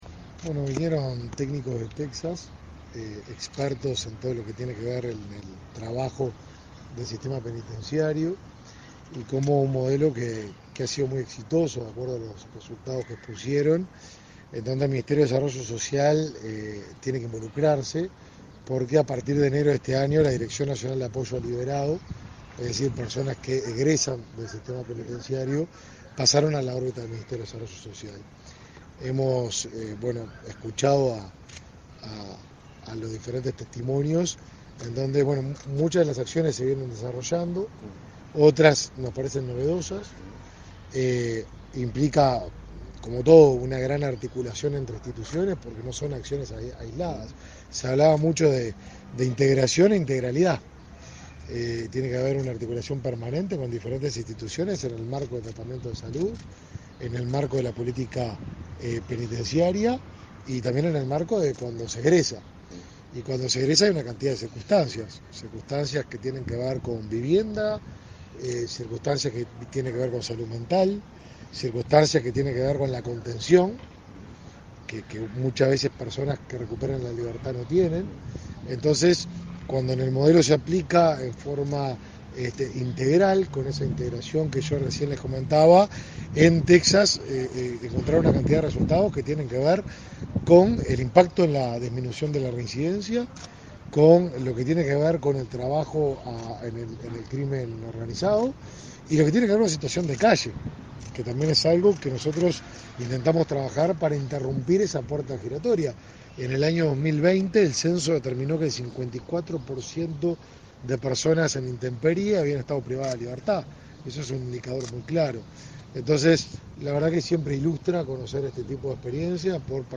Declaraciones a la prensa del ministro de Desarrollo Social, Martín Lema
En la oportunidad, expertos de ese país explicaron cómo se realizó la reforma penitenciaria en Texas. Tras el encuentro, el ministro Martín Lema realizó declaraciones a la prensa.